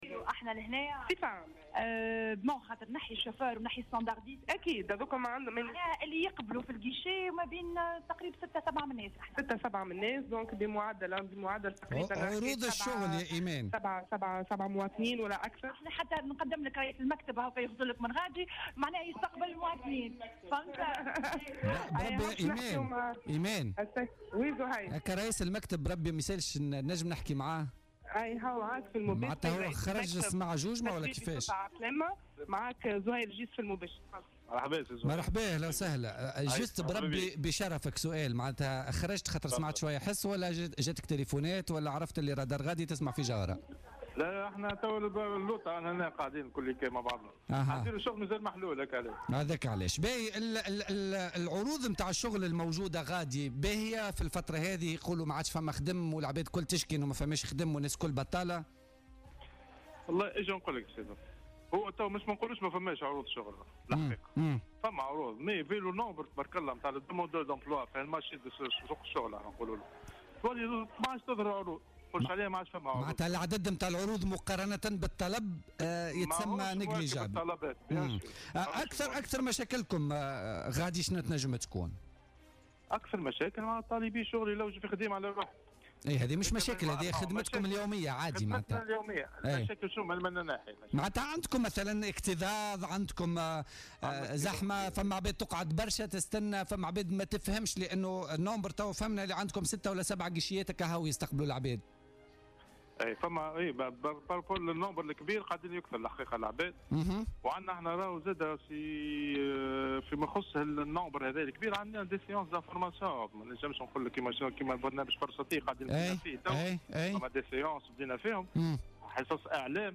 الرادار بمكتب التشغيل بسوسة